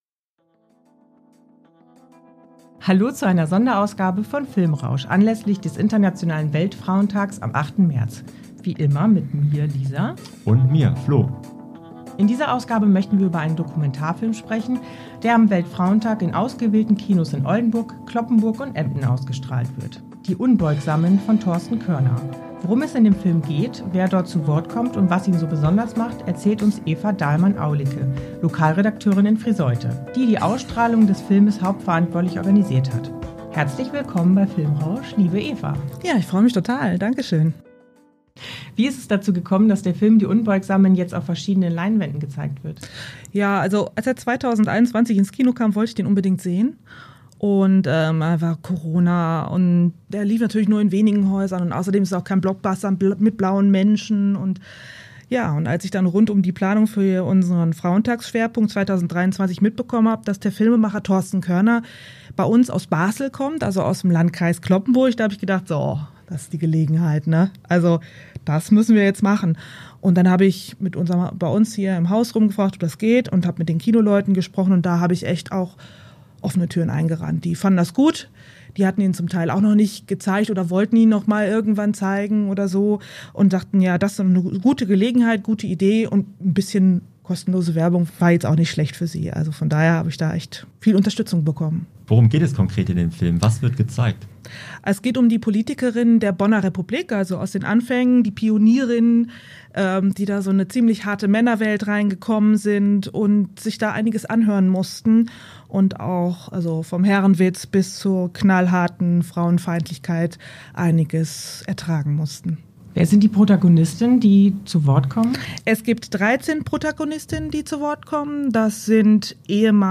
In dieser Sonderfolge interviewen wir